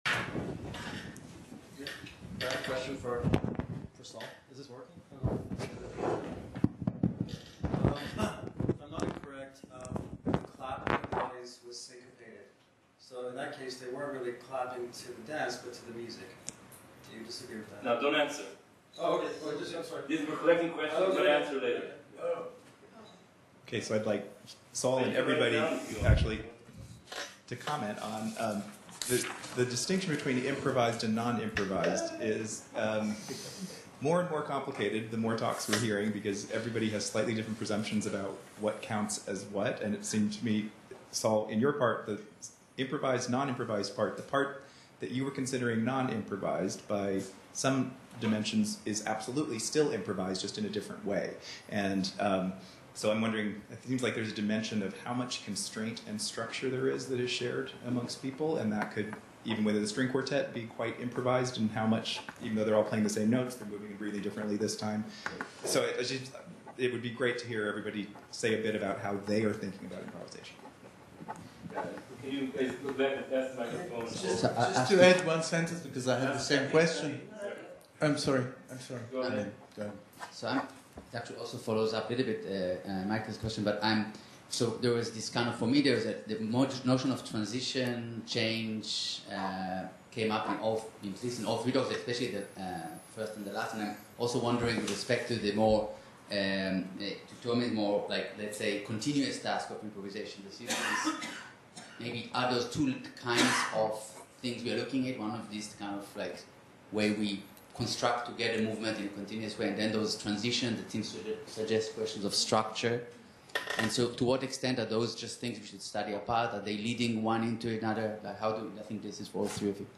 “Quantifying JI” Debate.